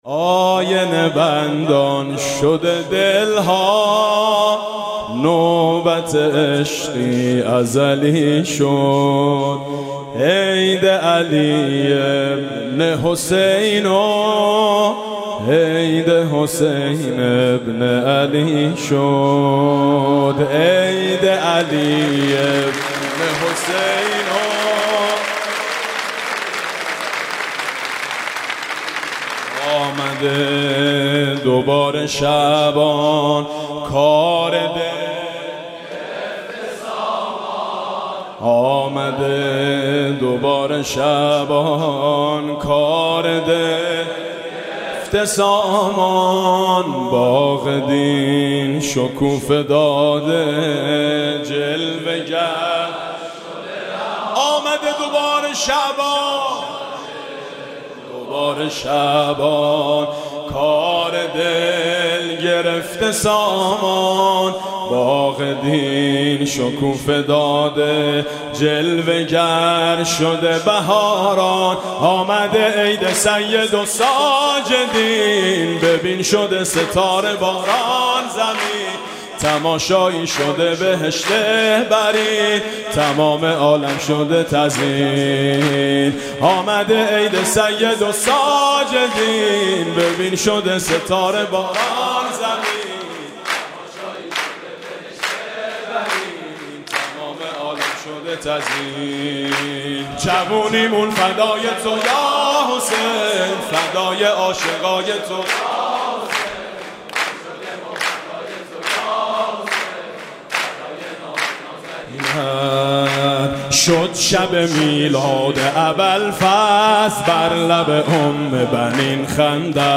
ویژه اعیاد شعبانیه